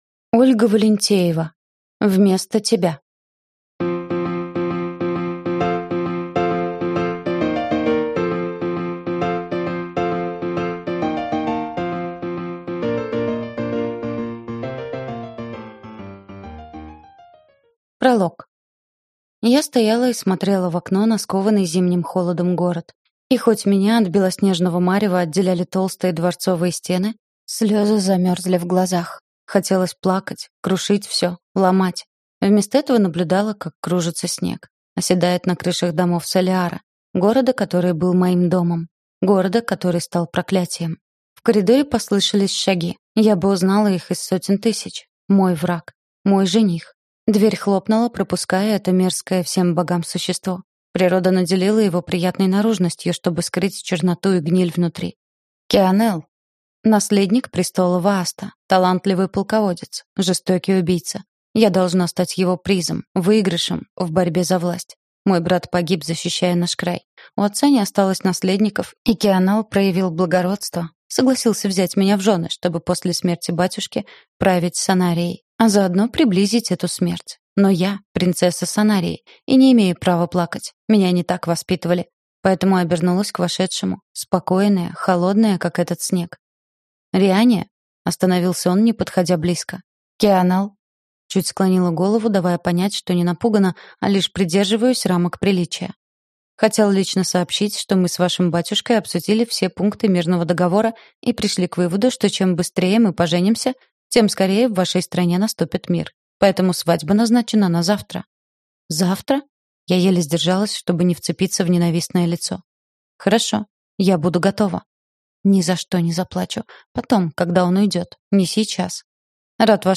Аудиокнига Вместо тебя | Библиотека аудиокниг